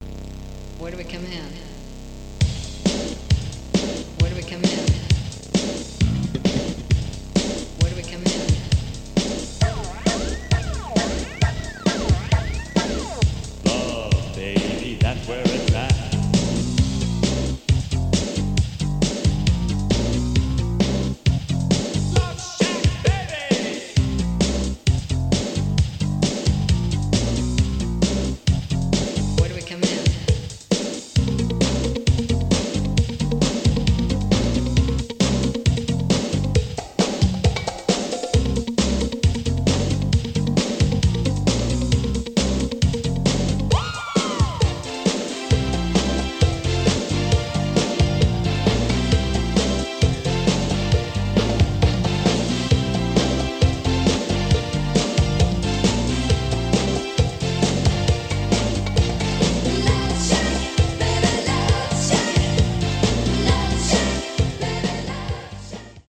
Below is a test recording made with the GX-65 on a Normal position tape and played back by it:
AKAI-GX-65MkII-Test-Recording.mp3